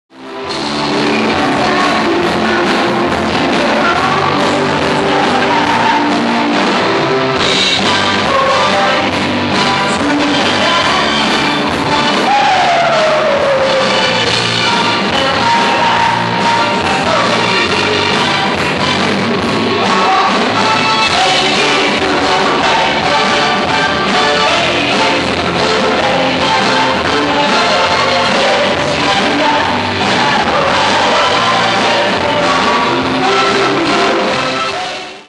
Nissan Prince Sound Contest 2nd Stage
プリンスサウンドコンテスト第二次予選